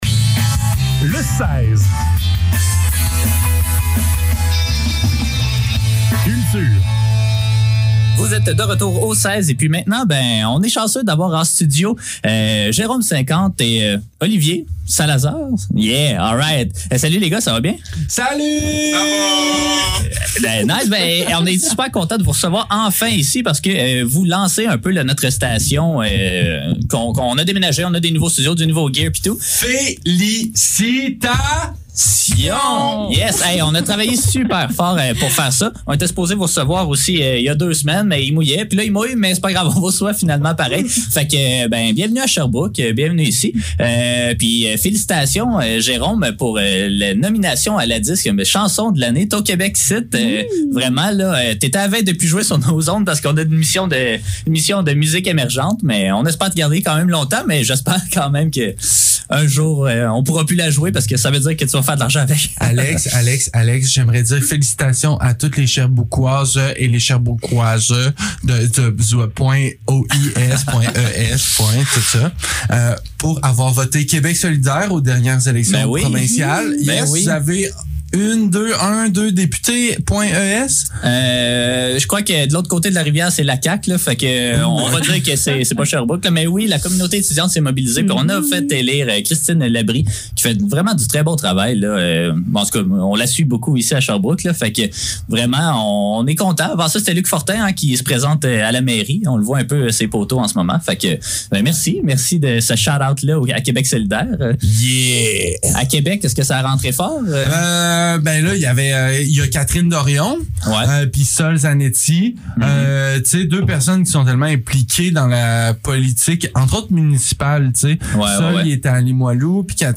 Le seize - Entrevue